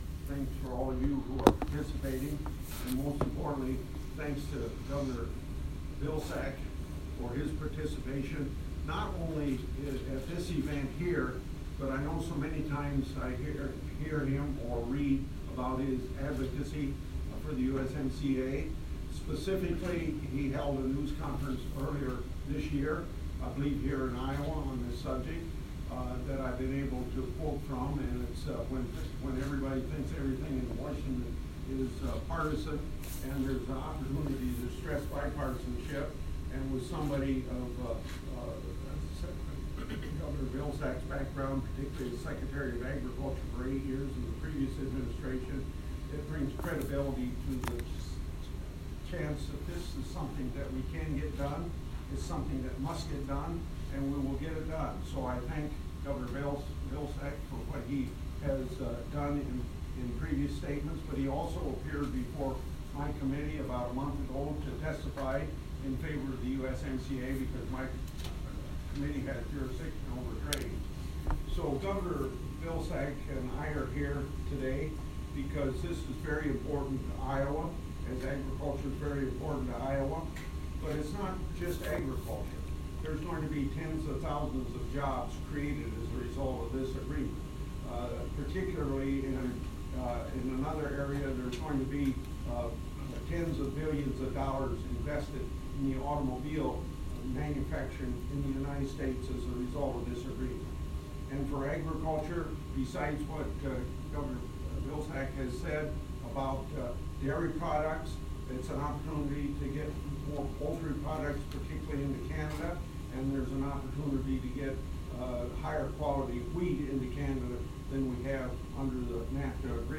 USMCA Opening Remarks 08-26-19